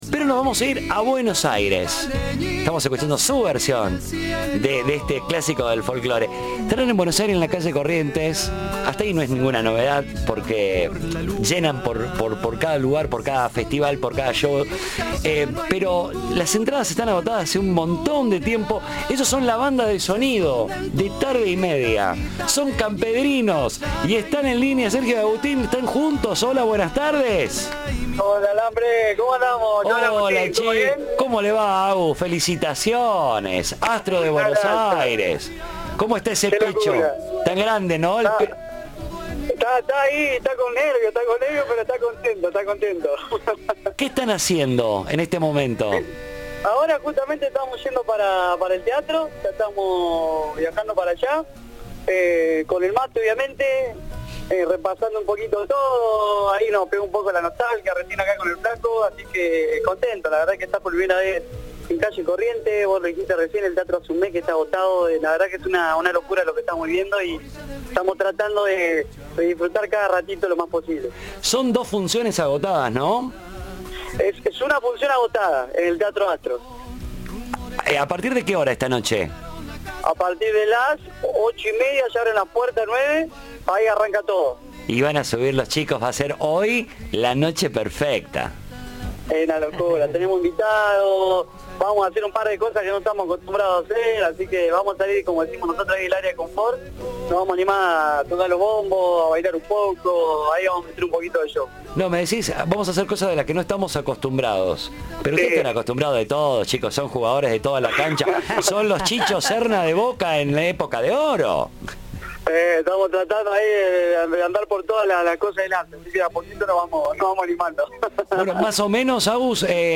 Campredinos se presentará este sábado por la noche en el Teatro Astral de calle Corrientes cerrando su gira "La noche perfecta" y no pudieron ocultar su "felicidad completa", en diálogo con Tarde y Media.